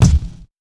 Wu-RZA-Kick 69.wav